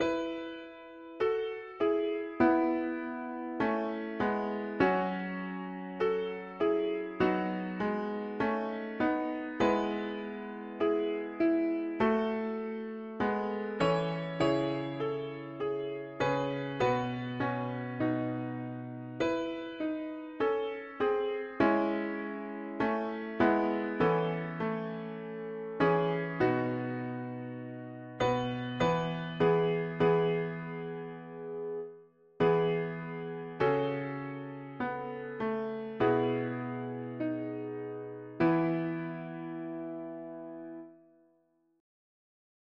Key: E major
Tags english secular 4part summer death